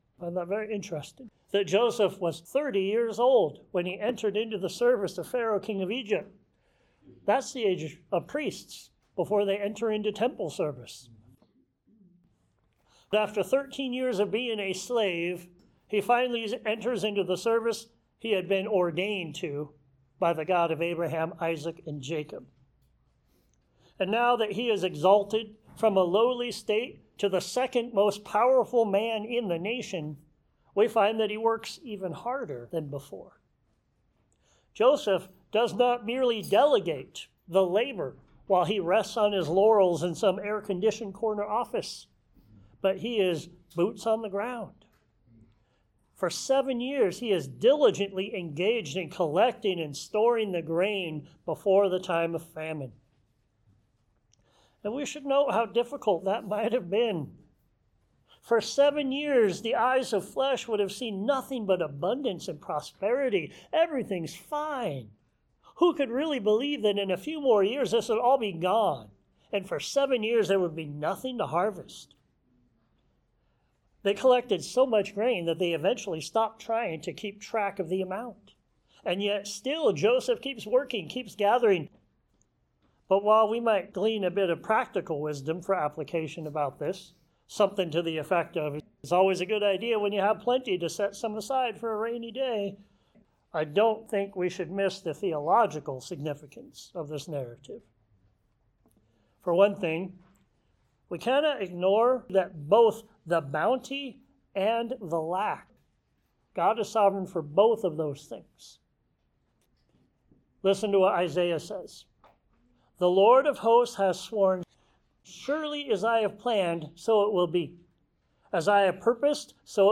Genesis 41-42 Beggars in Need of Bread Sermons Share this: Share on X (Opens in new window) X Share on Facebook (Opens in new window) Facebook Like Loading...